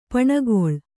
♪ paṇagoḷ